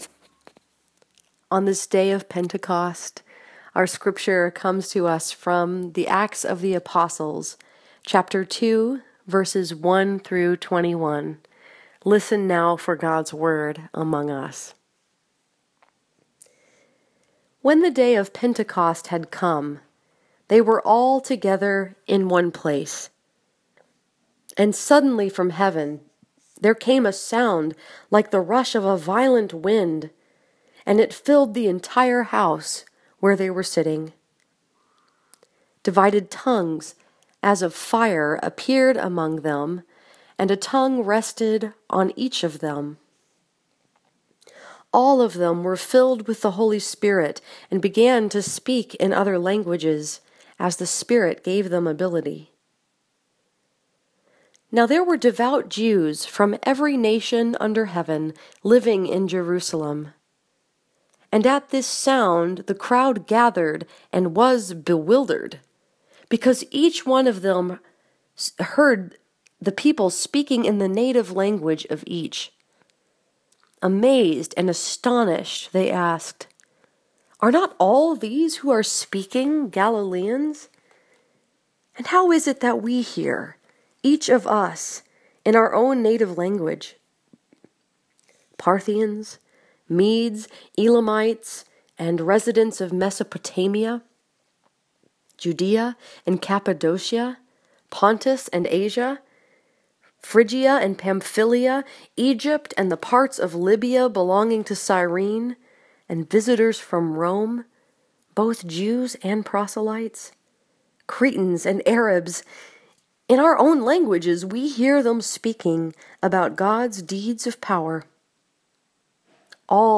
This sermon was preached at St. Andrew’s Presbyterian Church in Dearborn Heights, Michigan and was focused upon the story that is told in Acts 2:1-21.